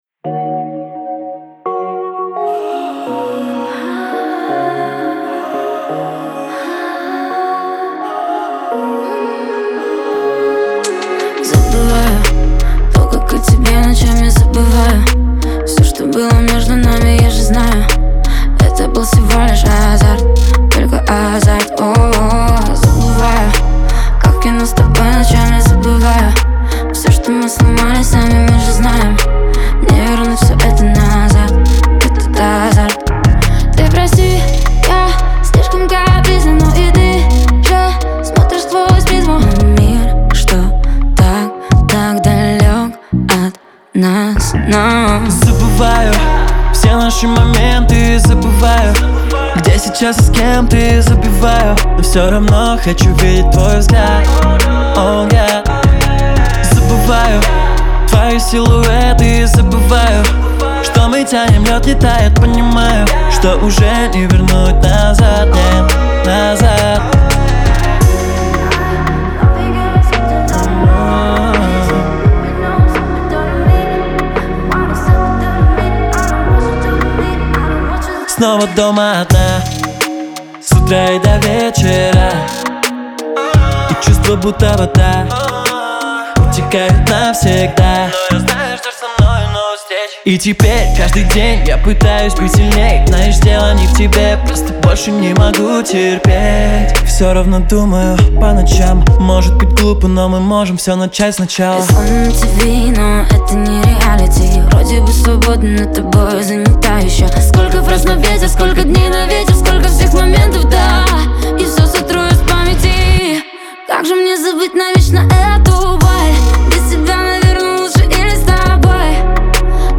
это современный поп-трек с меланхоличным настроением.